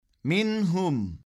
Her iki harf açık ve net bir şekilde, peş peşe yani seslere ara vermeden okunmalıdır.